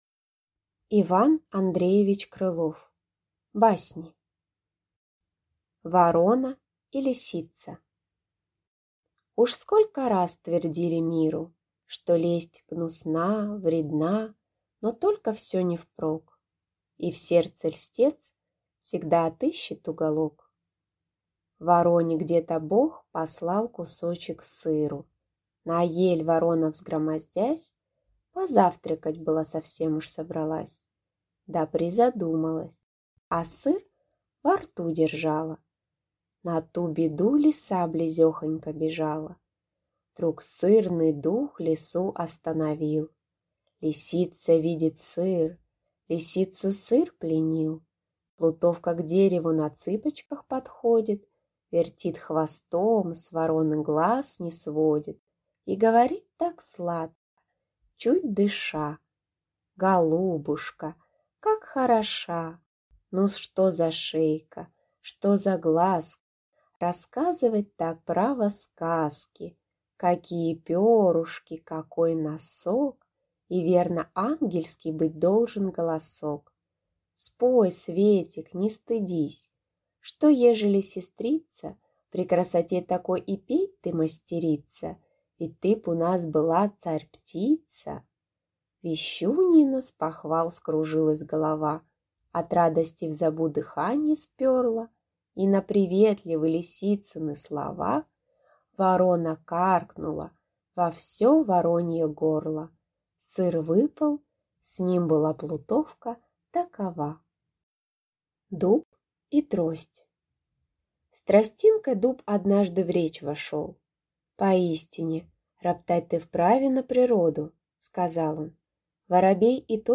Аудиокнига Басни | Библиотека аудиокниг